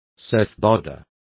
Complete with pronunciation of the translation of surfboarders.